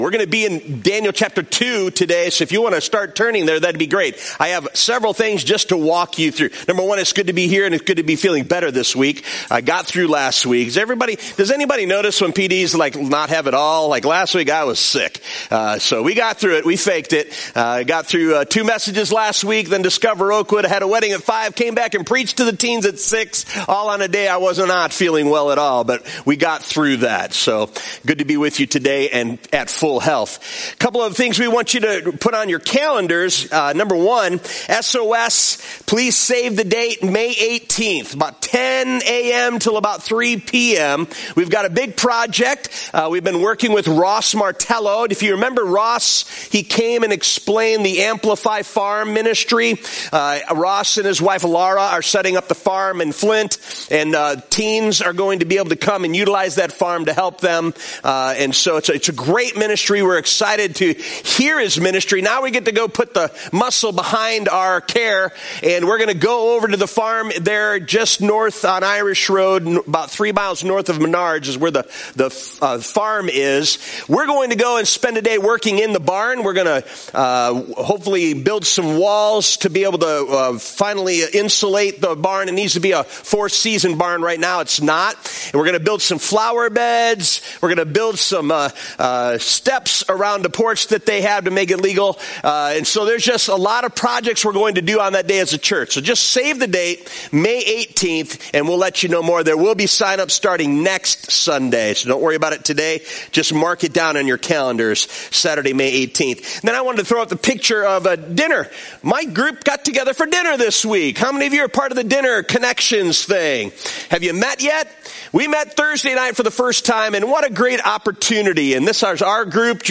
Oakwood Community Church Message Podcast | Oakwood Community Church